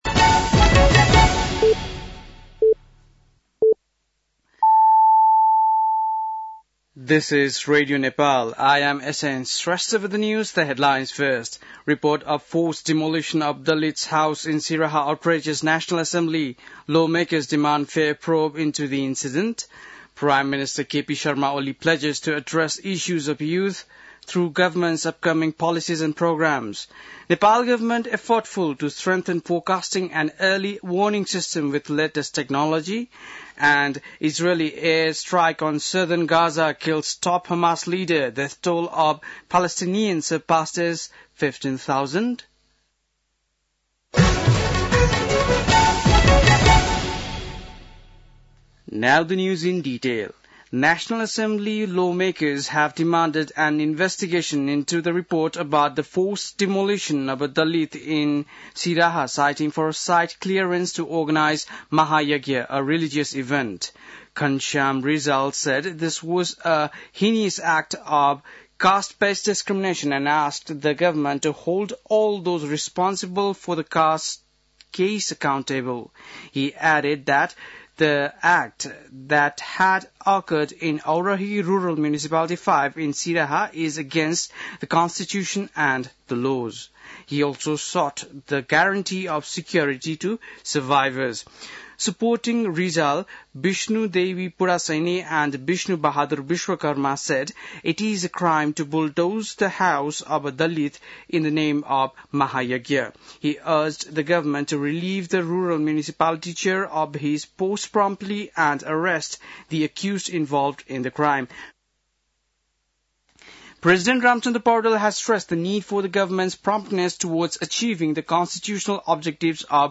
बेलुकी ८ बजेको अङ्ग्रेजी समाचार : १० चैत , २०८१
8-pm-english-news-12-10.mp3